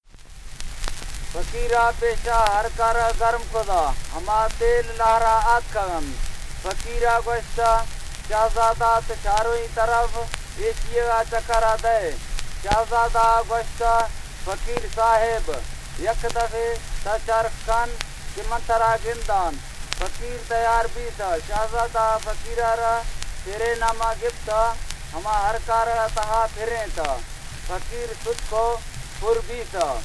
100 year old recordings that are well preserved are rather rare, and due to the linguist Wilhelm Doegen’s aim to record as many different languages and dialects as he could, the Berlin Lautarchiv holds a fascinating collection of songs, prayers, tales and personal narrations.
Extracts from the archive recordings
„Tale“